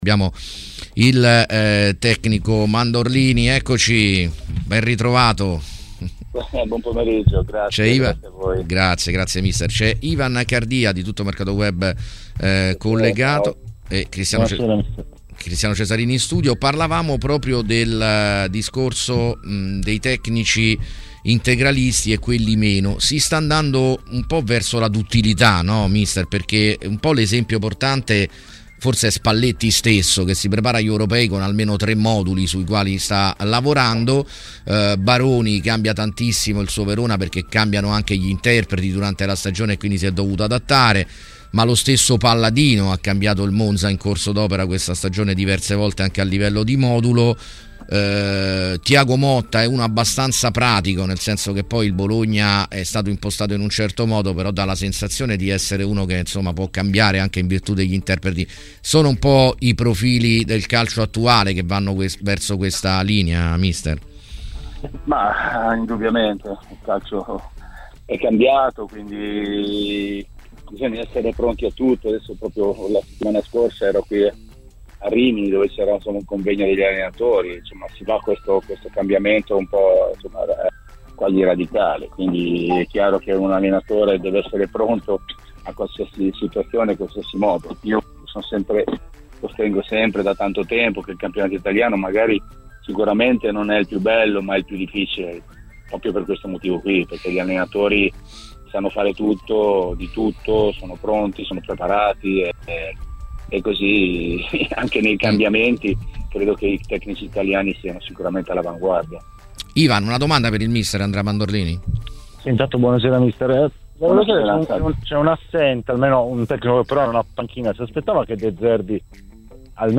Andrea Mandorlini, allenatore, ha parlato a TMW Radio di alcuni temi relativi alla Serie A e non solo.